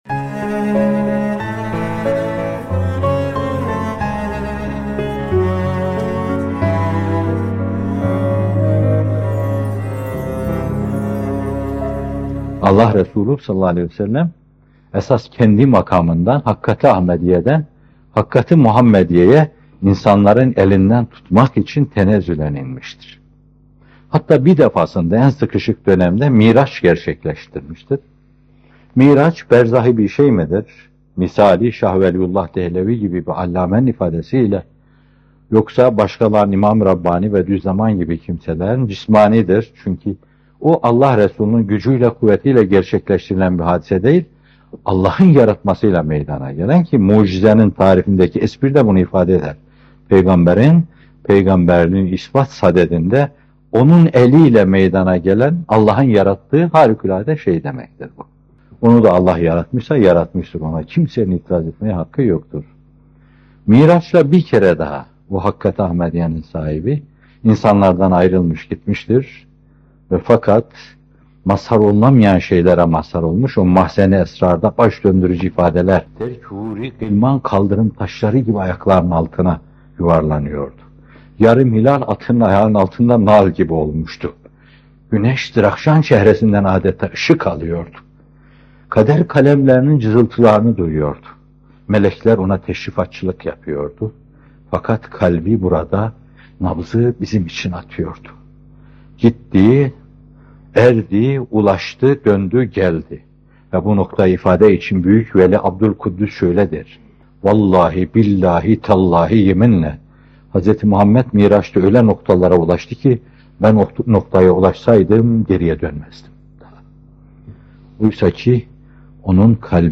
Miraç ve Efendimiz’in (sav) İnsanlığa Sevgisi - Fethullah Gülen Hocaefendi'nin Sohbetleri